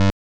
snd_push.wav